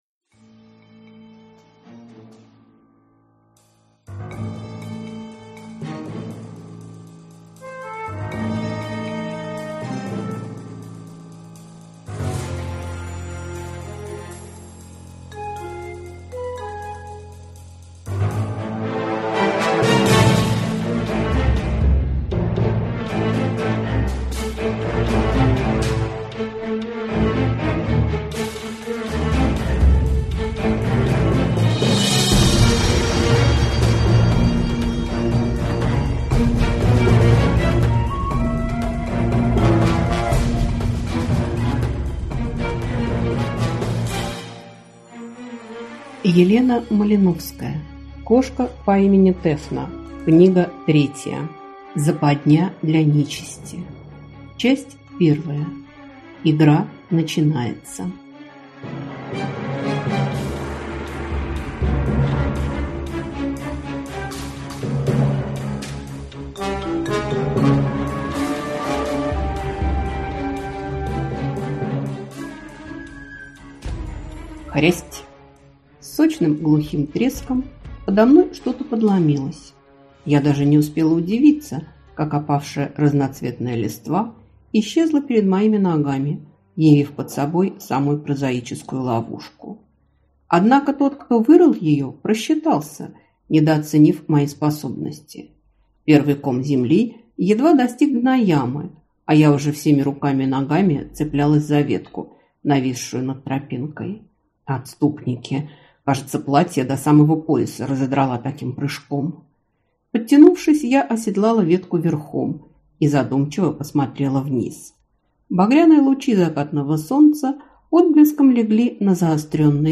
Аудиокнига Западня для нечисти | Библиотека аудиокниг